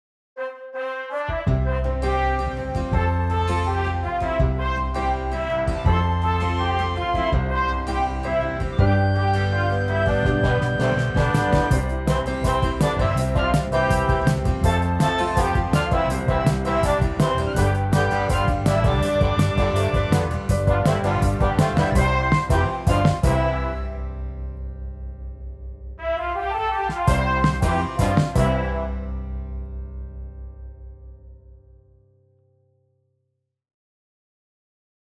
• Même musique :